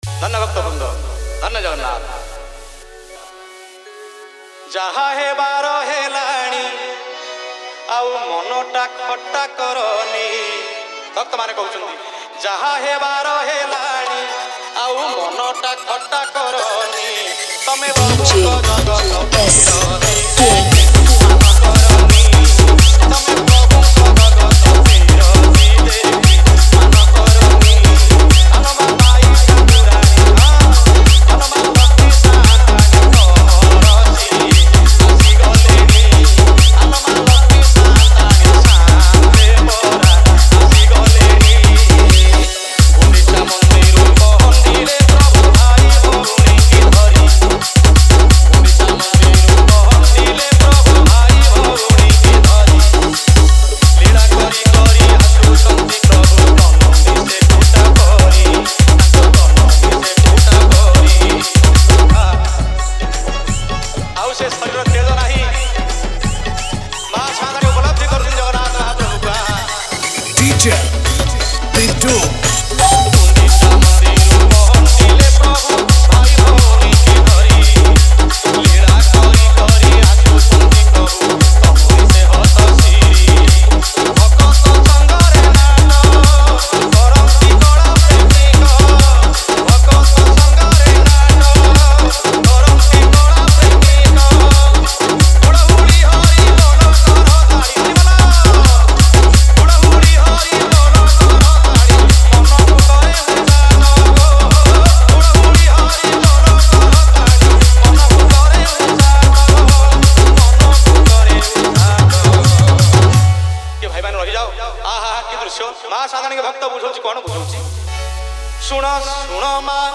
Bhajan Dj Song Collection 2023 Songs Download